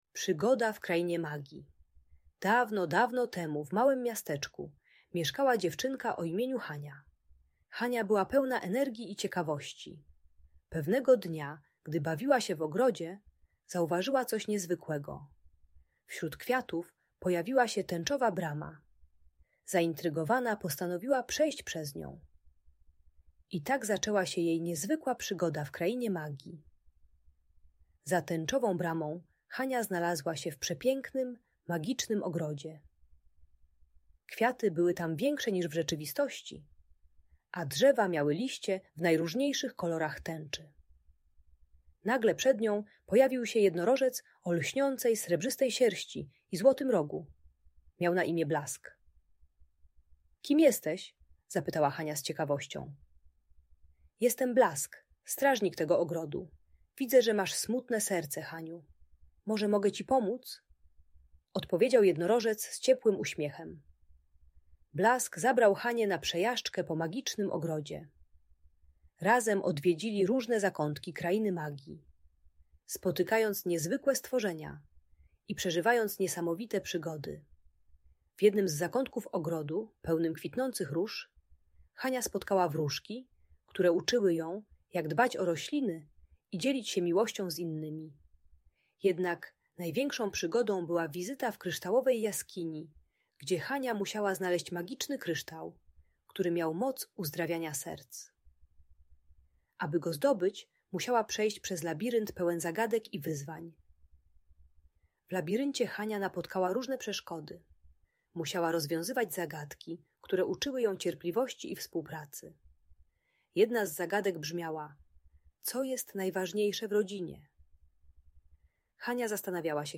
Magiczna przygoda Hani - Rodzeństwo | Audiobajka